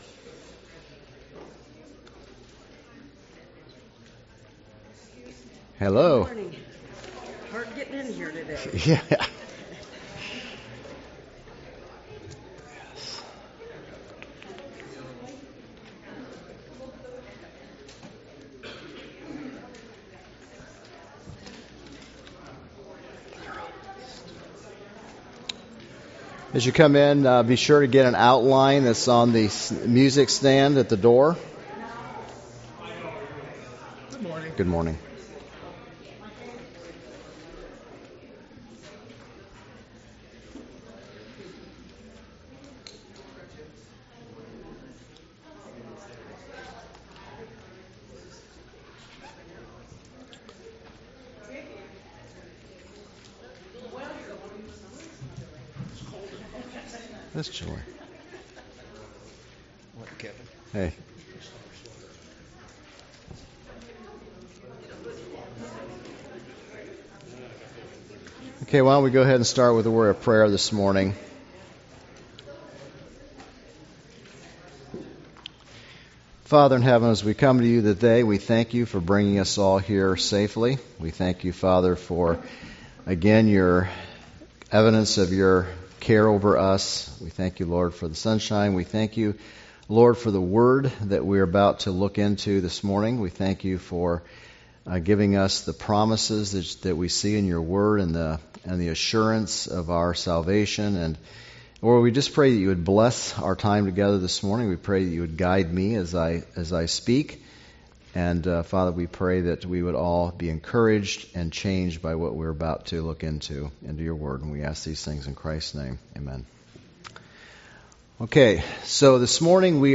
Minor Prophets Passage: Zechariah 6 Service Type: Sunday School « Sorrento